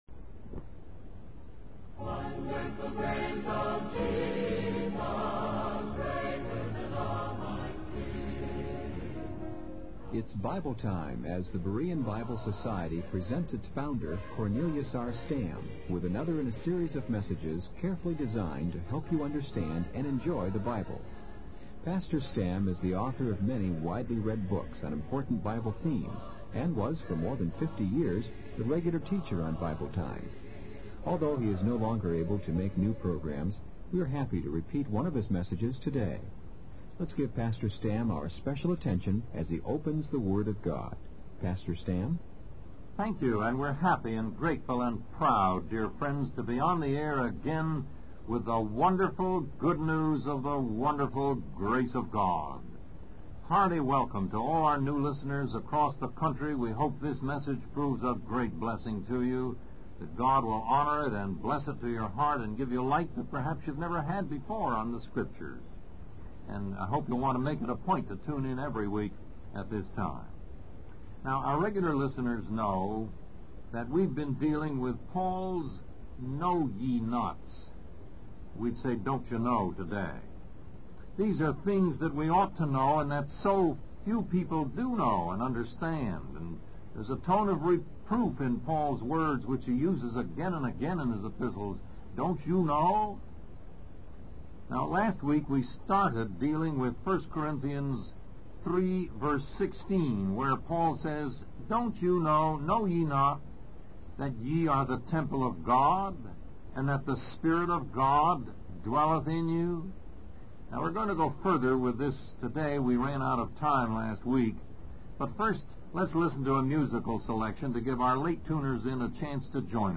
Lesson 3: Let Every Man Take Heed